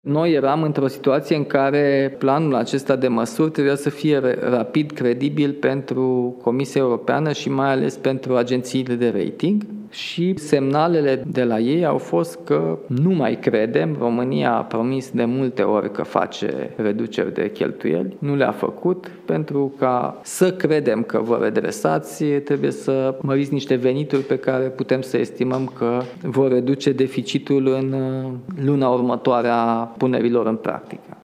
Președintele Nicușor Dan a susținut luni o conferință de presă la Palatul Cotroceni.